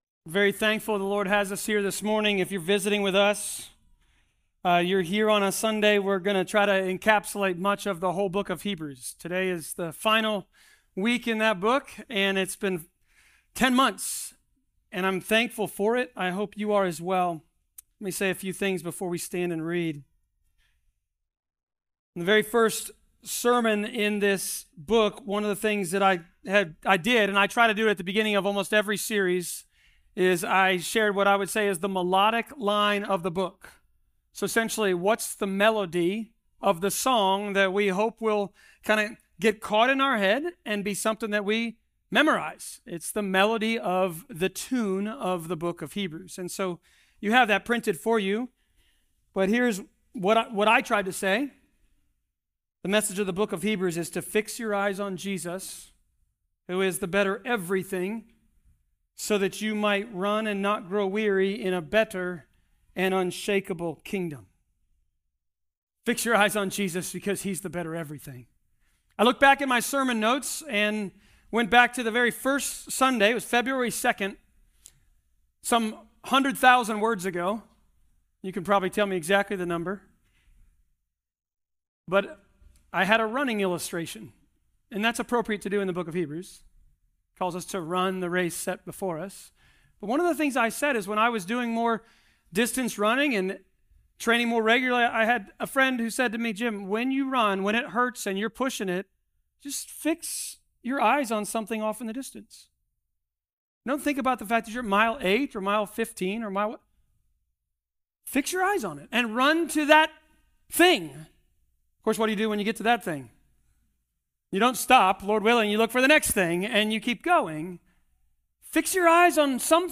Hebrews Passage: Hebrews 13.20-25 Service Type: Sermons « INSANELY PRACTICAL